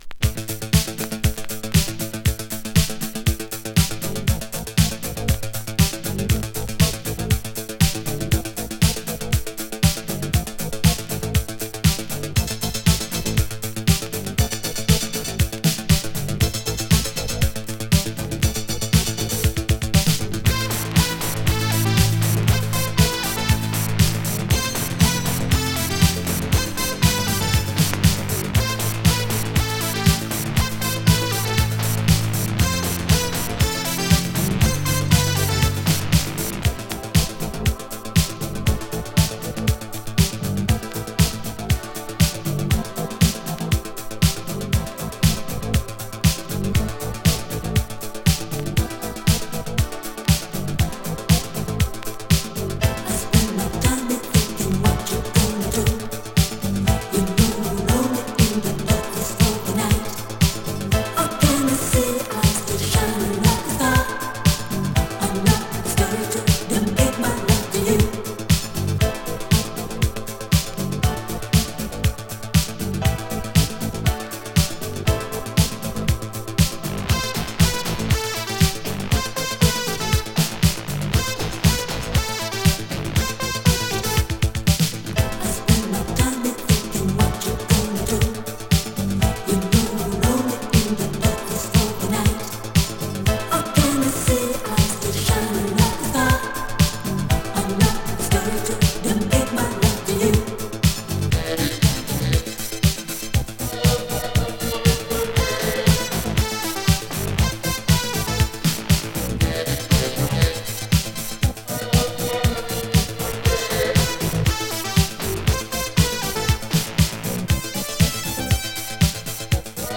【ITALO DISCO】